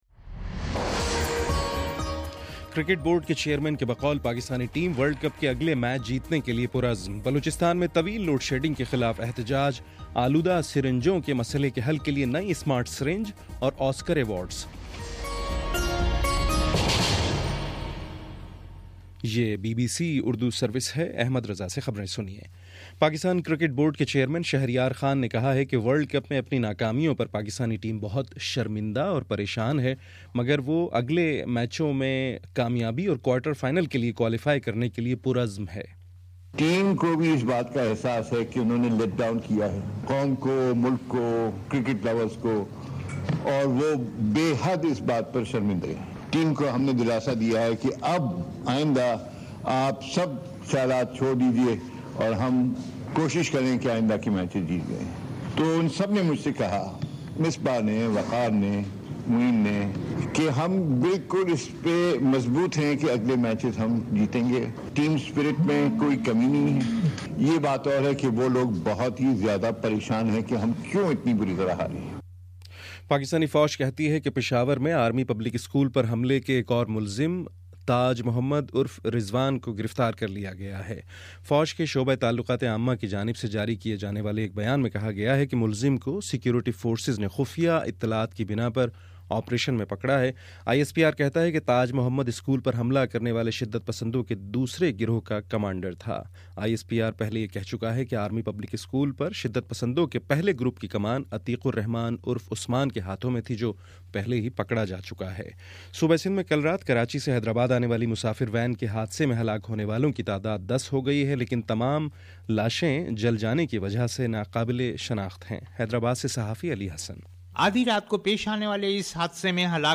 فروری23: شام سات بجے کا نیوز بُلیٹن